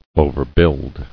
[o·ver·build]